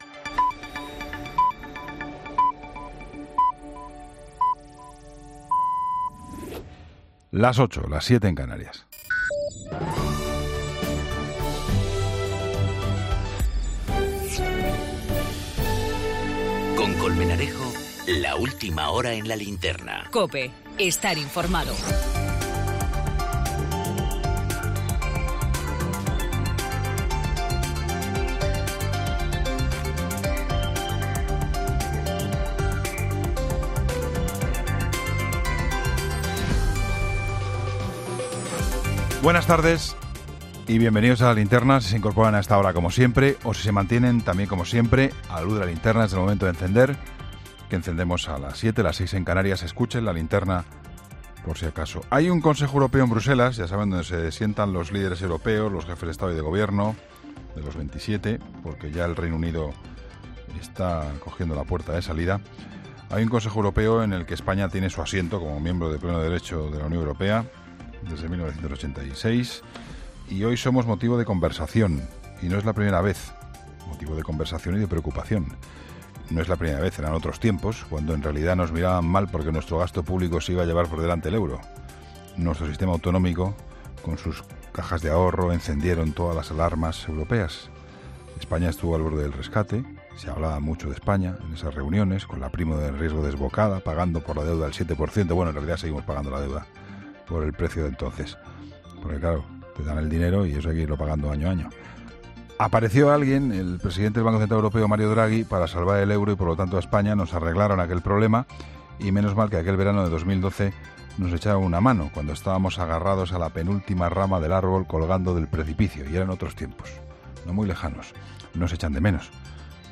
La crónica